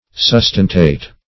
Sustentate \Sus"ten*tate\, v. t.